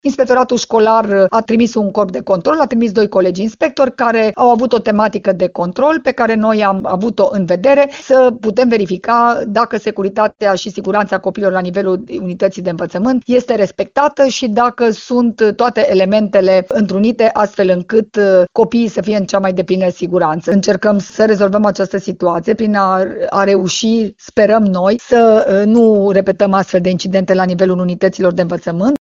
Inspectorul școlar general, Aura Danielescu, a declarat că se iau măsuri pentru ca astfel de incidente să nu se mai repete.